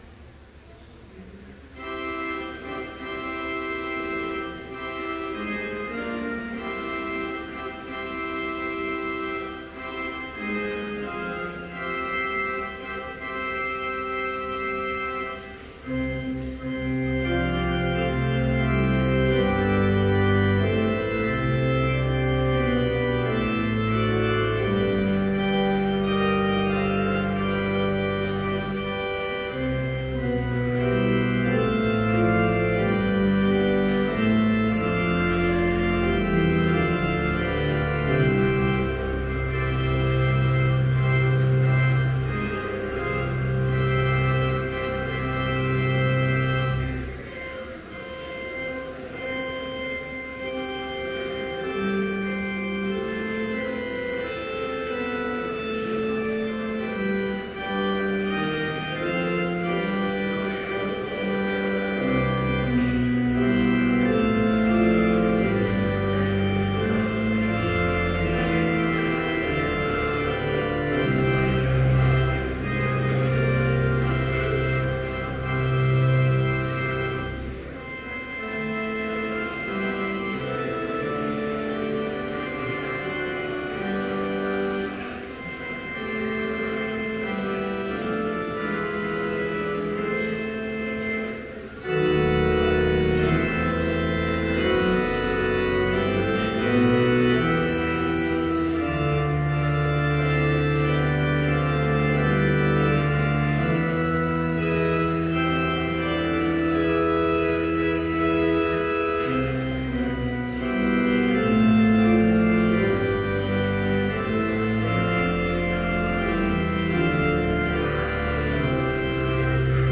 Complete service audio for Chapel - October 29, 2014